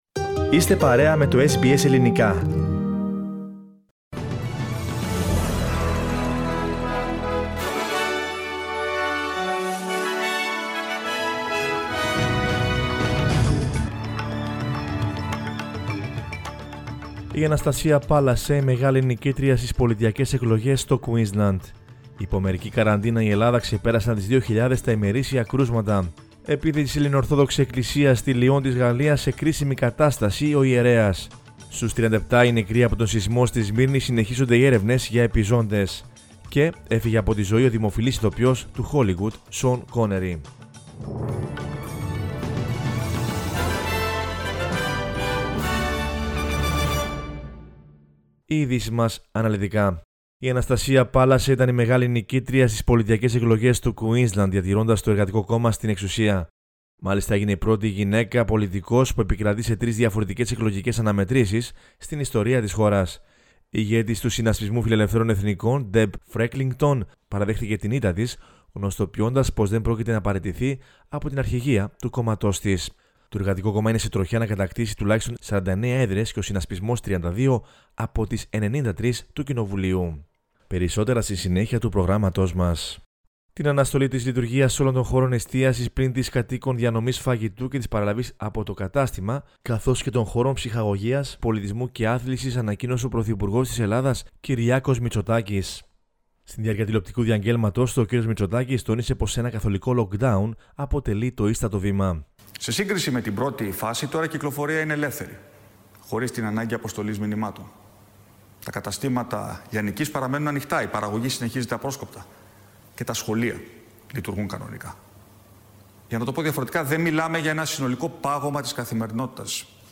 News in Greek from Australia, Greece, Cyprus and the world is the news bulletin of Sunday 1 November 2020.